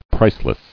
[price·less]